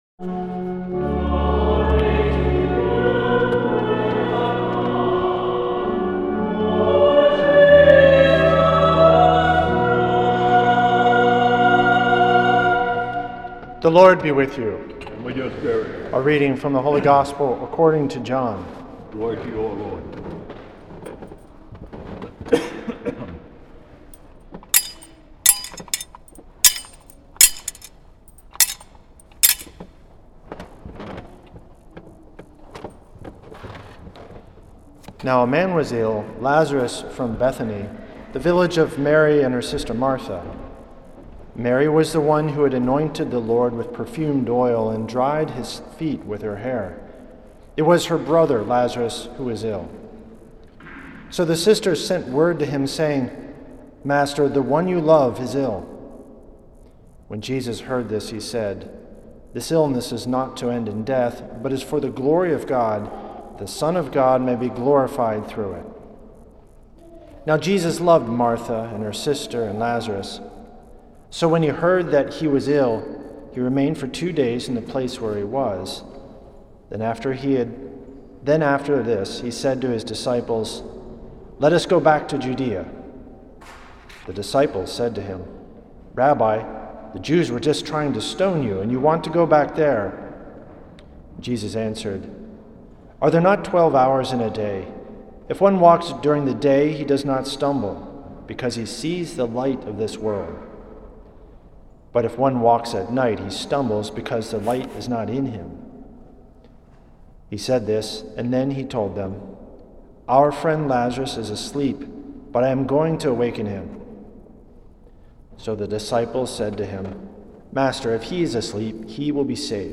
Homily
at St. Patrick’s Old Cathedral in NYC on March 22nd